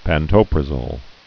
(păn-tōprə-zōl)